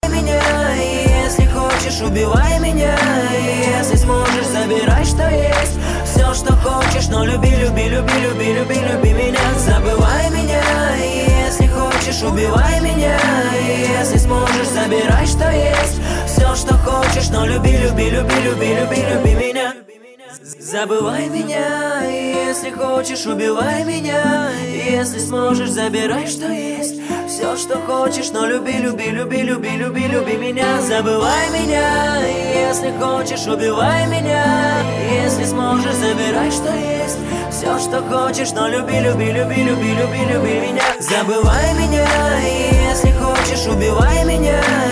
• Качество: 170, Stereo
мужской вокал
романтичные